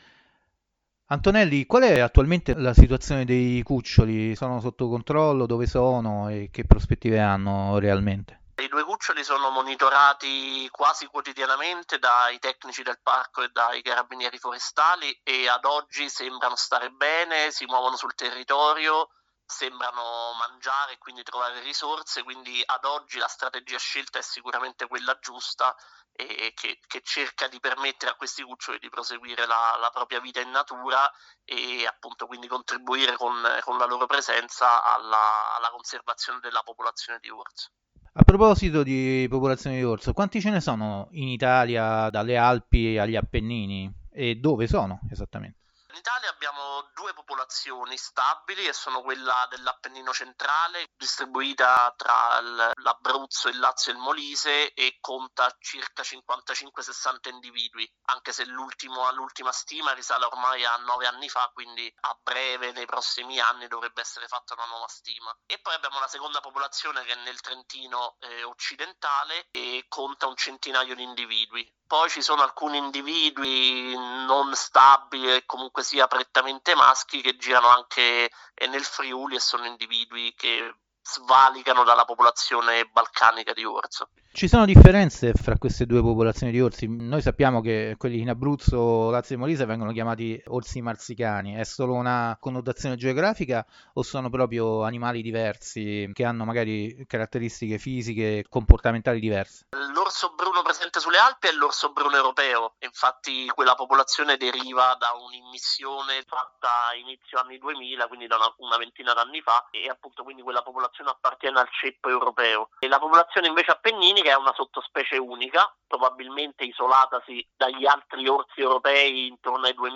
Ecosistema Interviste